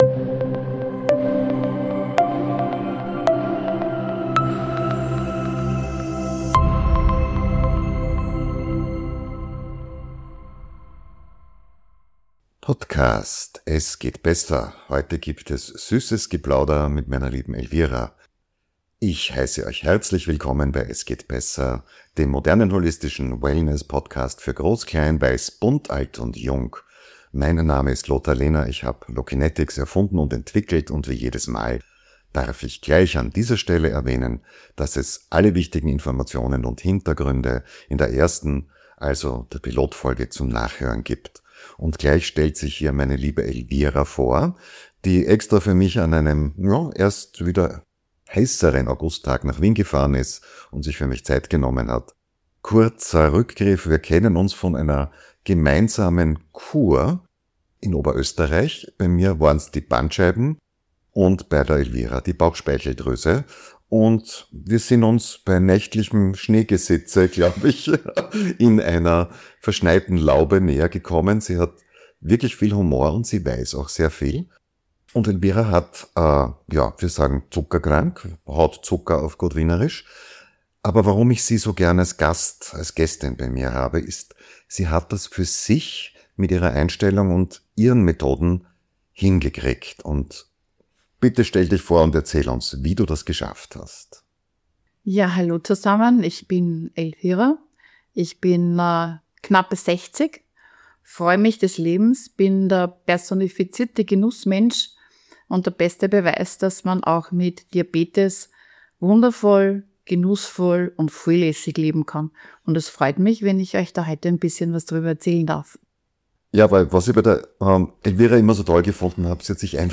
Ein ehrliches Gespräch über Ernährung, Achtsamkeit und Selbstfürsorge, das Mut macht, Verantwortung zu übernehmen, ohne Lebensfreude zu verlieren.